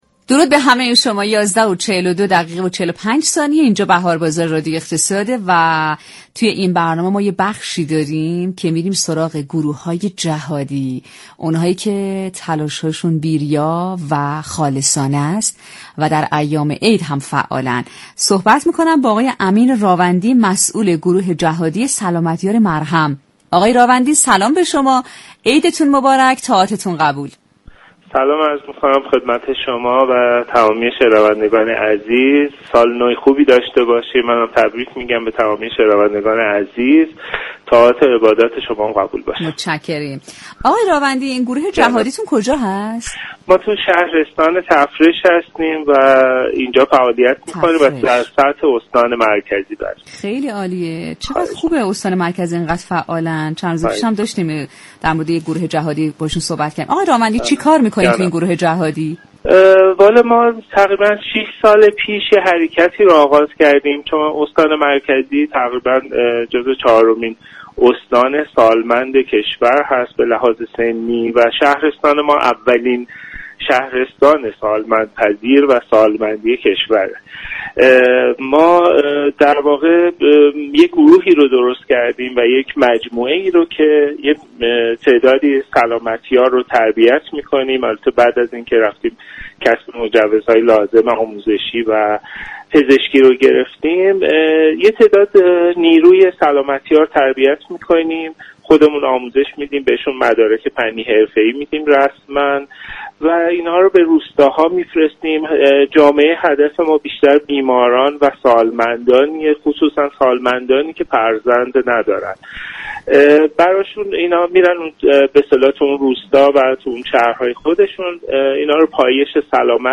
مصاحبه رادیویی